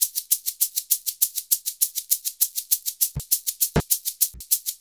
100SHAK02.wav